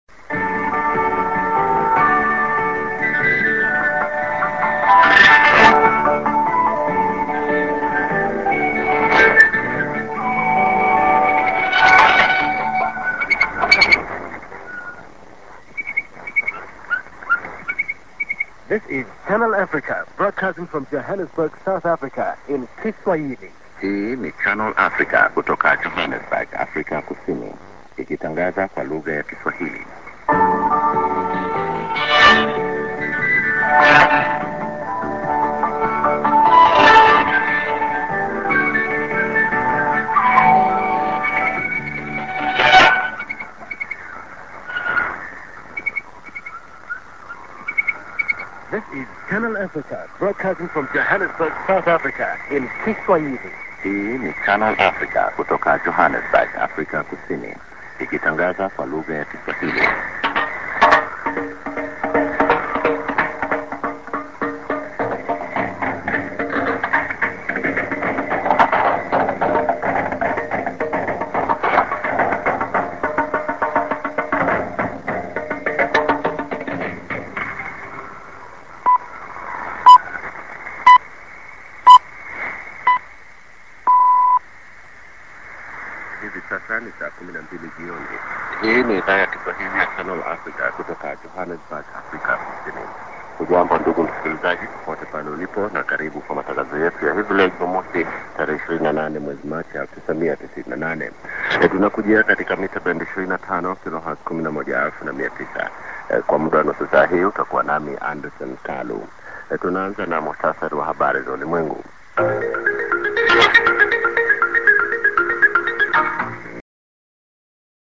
St. IS+ID(man)Rep.->ST(Duram)->01'20":TS->ID(man)